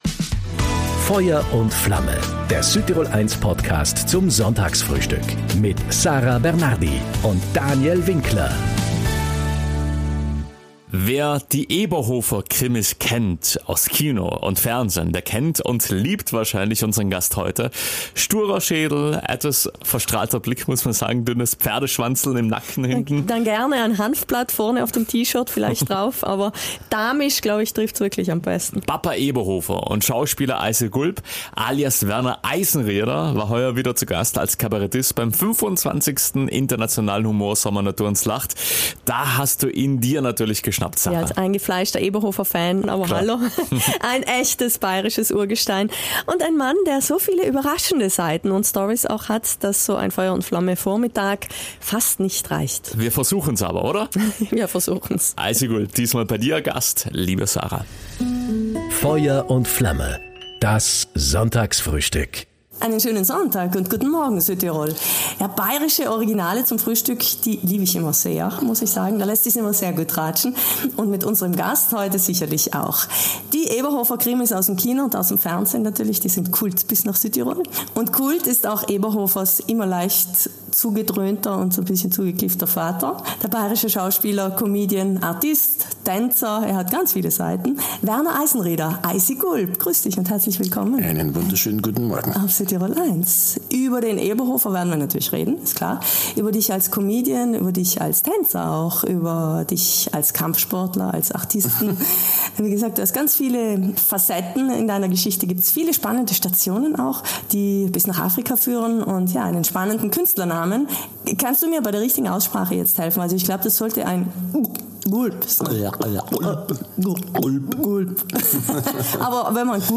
Schauspieler Eisi Gulp
im Gespräch